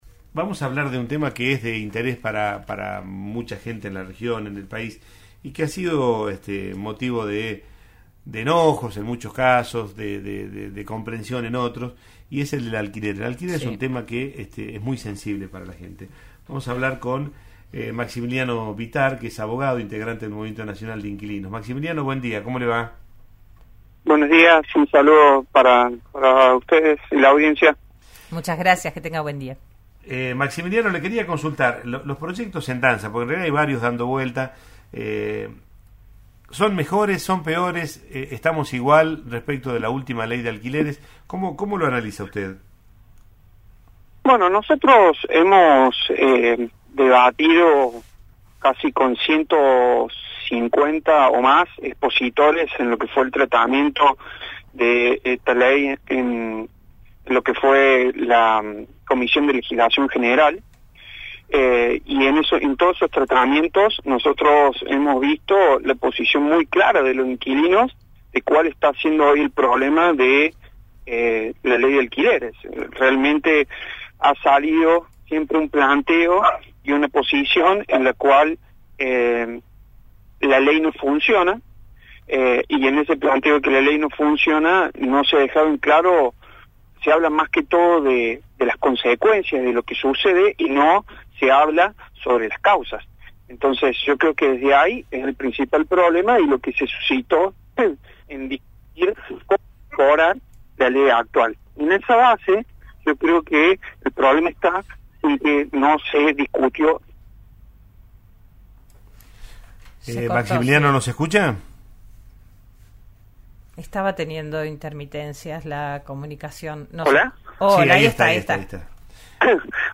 Así lo expresó en diálogo con RN Radio